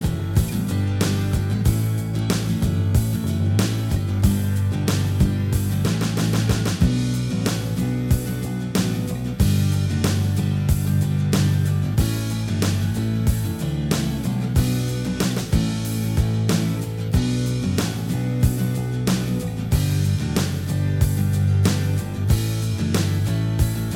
Minus Lead Guitar Rock 3:46 Buy £1.50